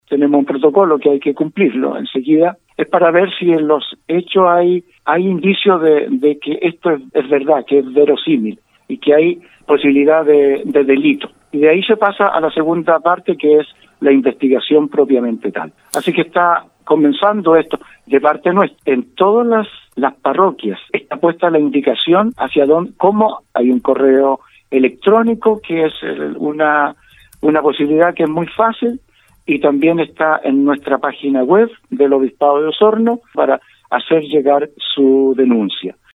Las declaraciones de la Fiscalía surgen tras el anuncio realizado por parte del Obispado de Osorno cuando informaron que realizarán una investigación canónica y en paralelo. El aún obispo de Osorno, Jorge Concha, indicó que este miembro de la iglesia osornina fue apartado de todo tipo de funciones laicas mientras siga la investigación.